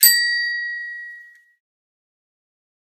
bicycle-bell_06
bell bells bicycle bike bright chime chimes clang sound effect free sound royalty free Memes